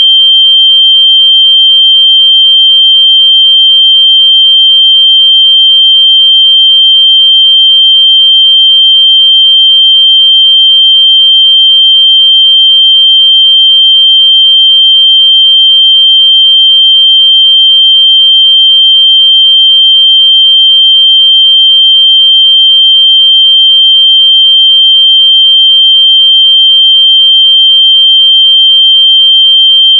下面是用Multi-Instrument的信号发生器生成的30秒长的标准测试信号（WAV文件），可供下载。